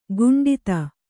♪ guṇḍita